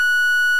Tap To Pay Beep Sound Button - Free Download & Play